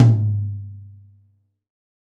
Q MTom42mx mf.WAV